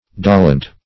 Search Result for " dolent" : The Collaborative International Dictionary of English v.0.48: Dolent \Do"lent\, a. [L. dolens, p. pr. of dolere: cf. F. dolent.